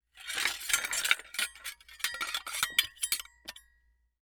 Metal_42.wav